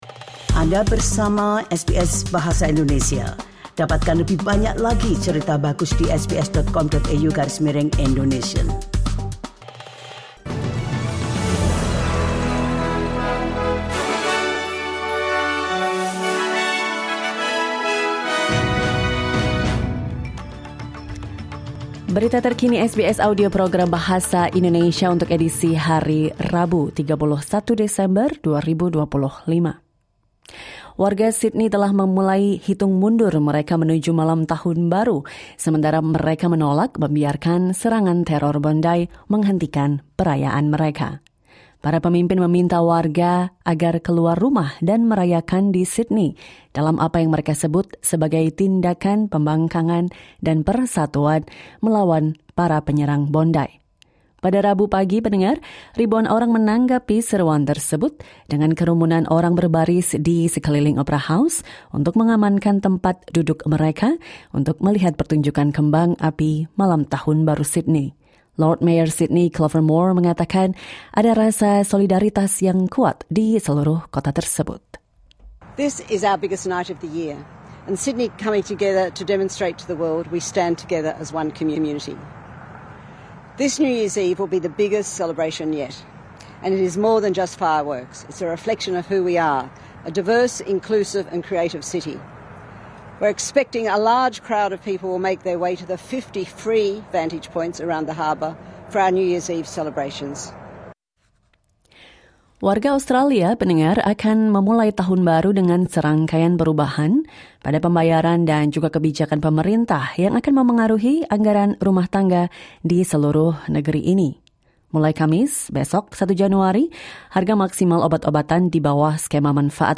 Berita Terkini SBS Audio Program Bahasa Indonesia - Rabu 31 Desember 2025